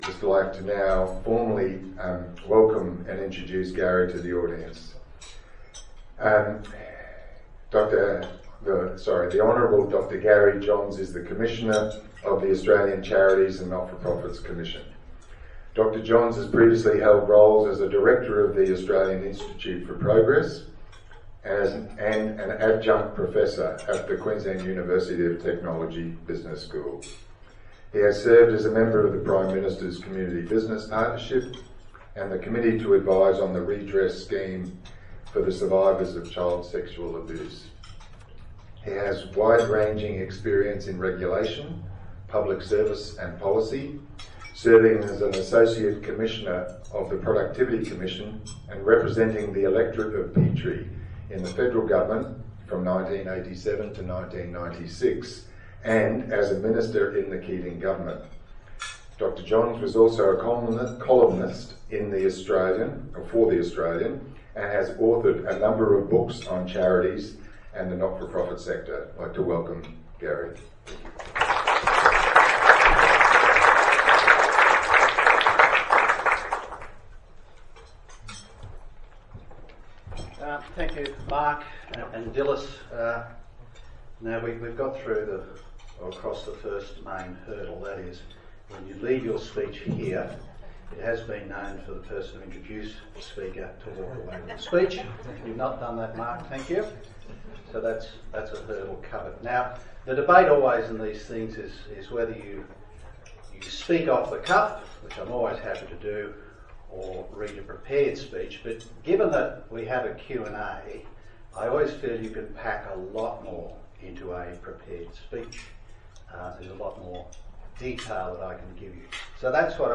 This audio file is from an update provided to leaders in the Charity and Not-for-Profit sector by Hon Dr Gary Johns, Commissioner of the ACNC, at the recent Windsor Group ACNC Update.